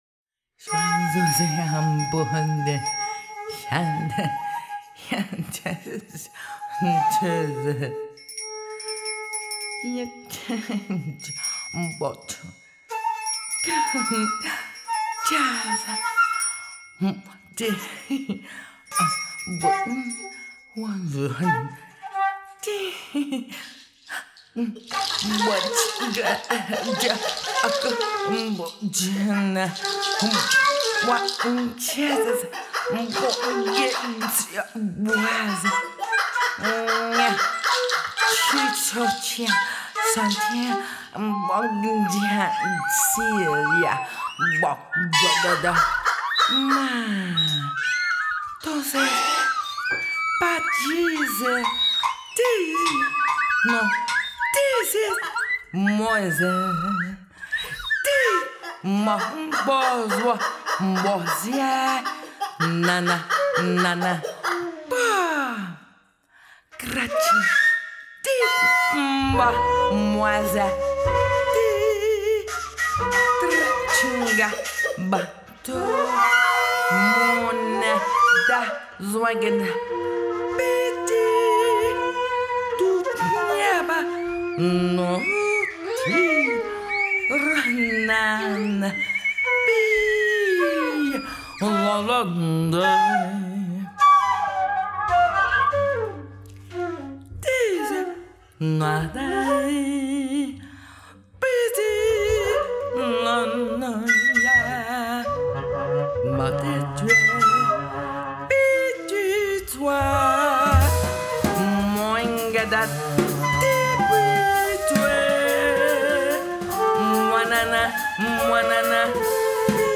vocals
alto sax, flute, bass clarinet
percussion